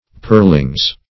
Search Result for " pearlings" : The Collaborative International Dictionary of English v.0.48: Pearlins \Pearl"ins\, Pearlings \Pearl"ings\, n. pl.
pearlings.mp3